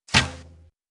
• 声道 立體聲 (2ch)